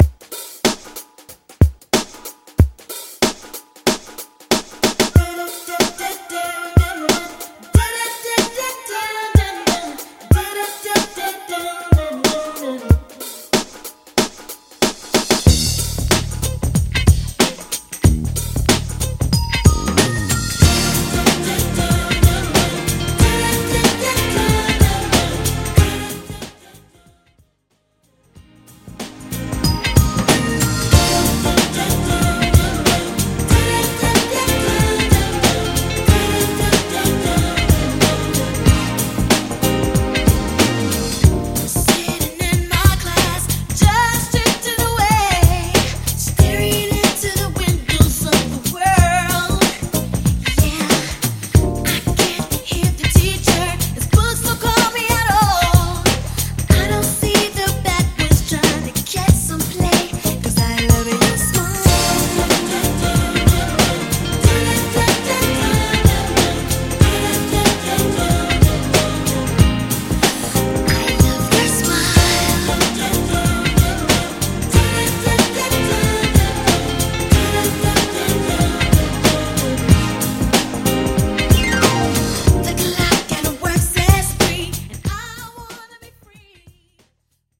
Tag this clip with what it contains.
MASHUPS , TOP40